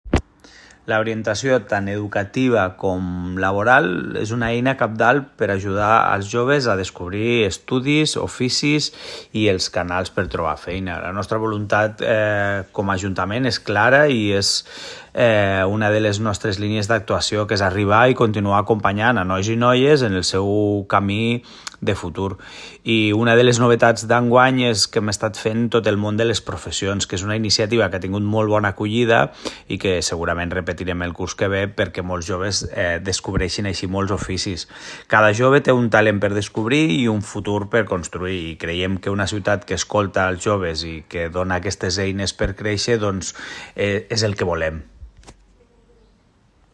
Tall de veu del regidor de Joventut, Xavi Blanco, sobre les visites als centres educatius per assessorar els i les joves en la recerca de feina